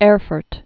(ĕrfərt, -frt)